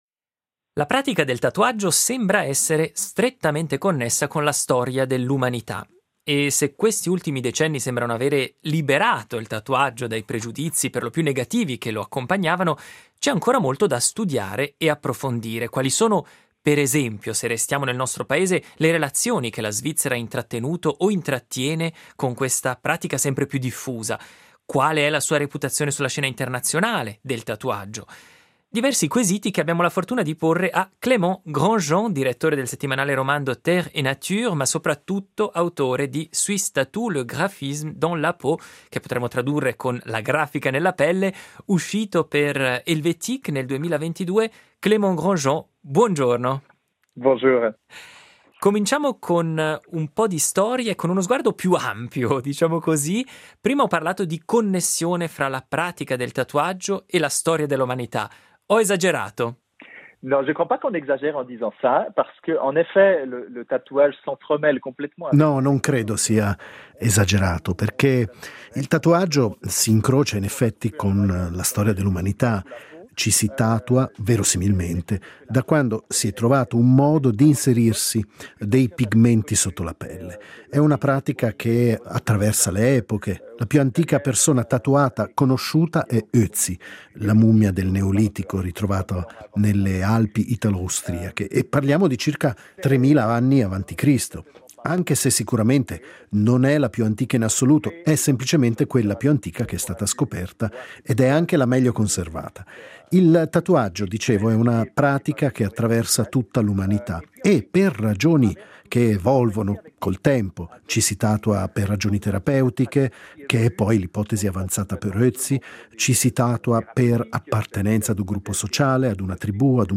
Intervistato il giornalista